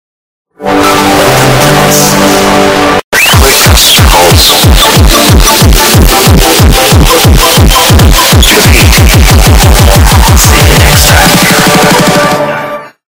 welcome-to-oss-earrape.mp3